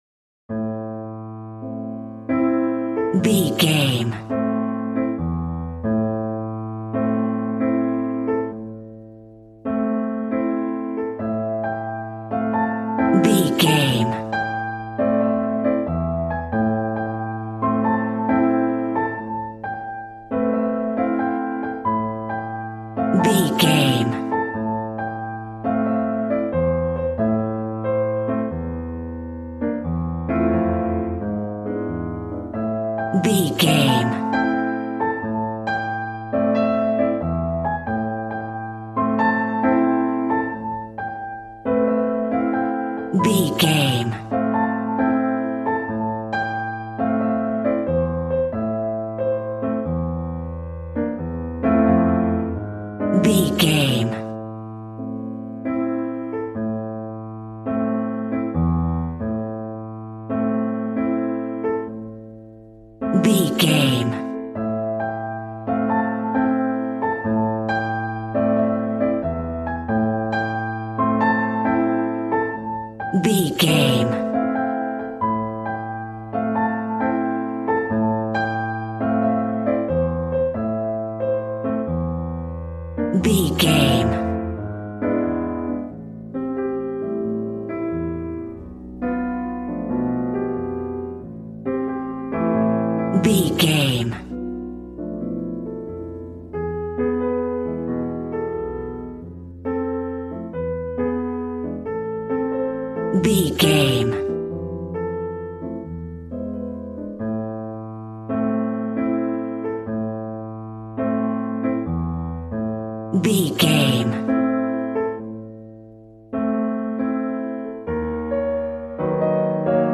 Smooth jazz piano mixed with jazz bass and cool jazz drums.,
Aeolian/Minor
piano
drums